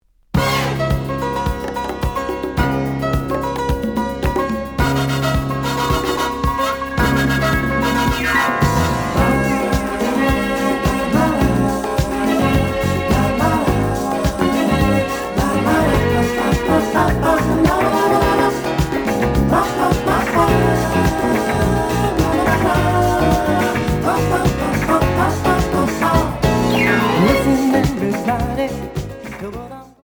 (Mono)
試聴は実際のレコードから録音しています。
●Genre: Disco